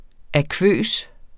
Udtale [ aˈkvøˀs ]